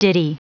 Prononciation du mot ditty en anglais (fichier audio)
Prononciation du mot : ditty